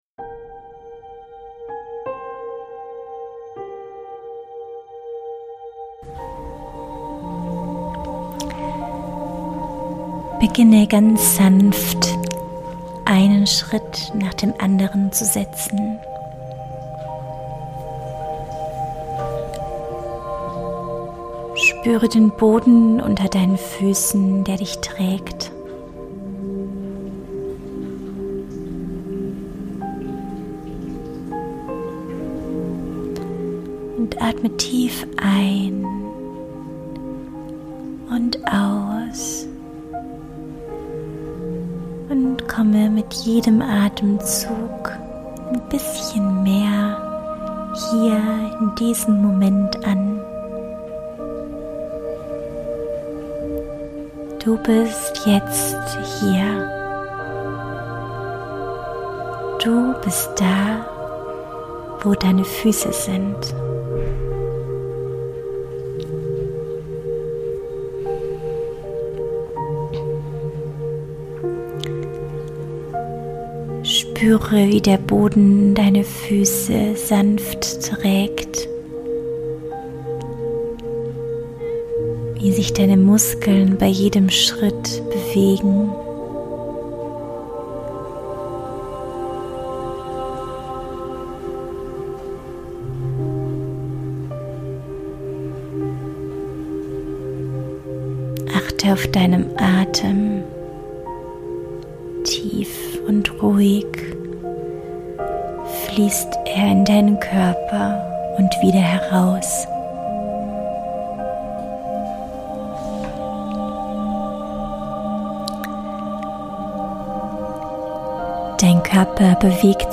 In dieser Gehmeditation für Eltern wirst du zu einem achtsamen Spaziergang eingeladen, der dir hilft, den Alltag hinter dir zu lassen und dich wieder mit dir selbst zu verbinden.